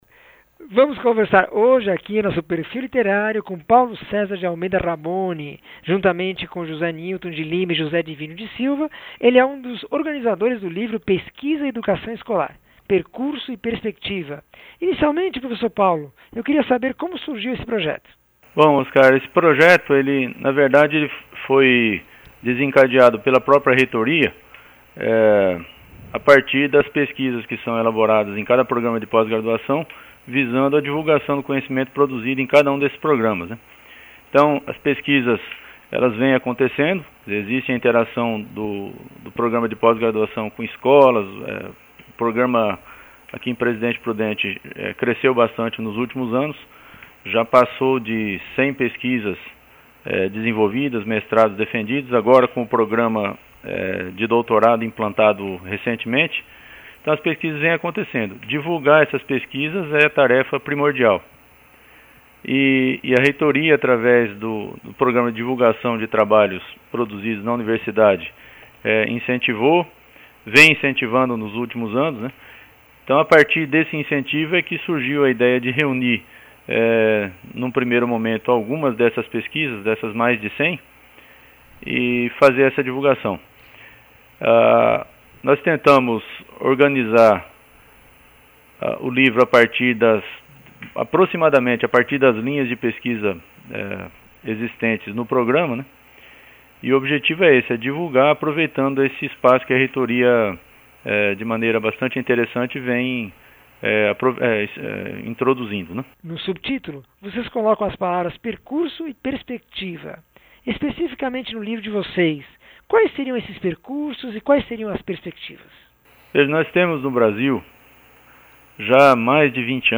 entrevista 1113